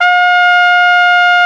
BRS CORNET09.wav